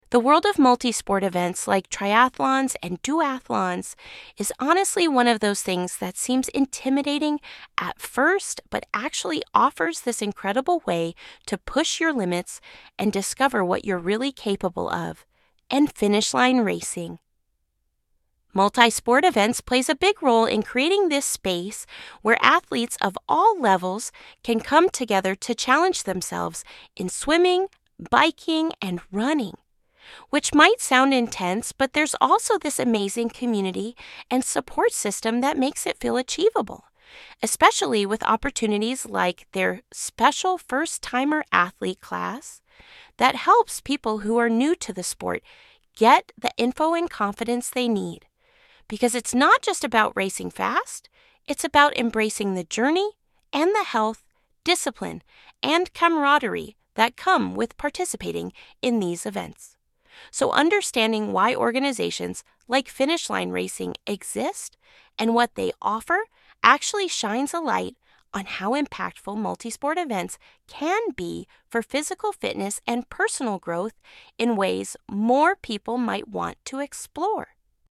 Voiceover video featuring essential tips for new triathlon participants, highlighting the special first timer athlete class happening May 2nd at PLAYTRI.